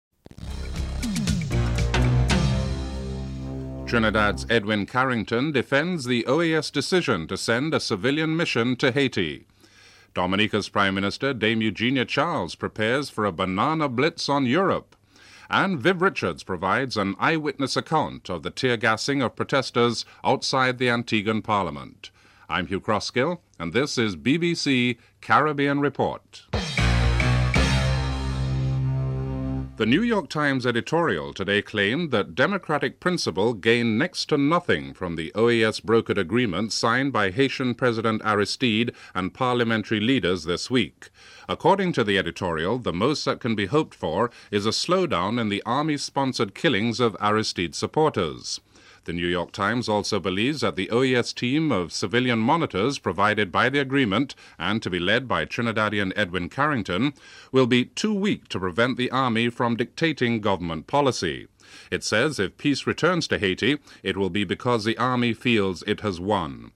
2. A New York Times editorial expresses doubts that OAS brokered agreements signed by Haitian President Aristide and parliamentary leaders will lead to a return of peace. CARICOM Secretary General Designate, Edwin Carrington expresses his faith in civilian intervention in an interview (00:29-03:44)
5. British Member of the European Parliament, Janey Buchan reveals plans to launch a campaign highlighting the negative side of Christopher Columbus and the 500th anniversary celebrations in an interview (05:37-08:58)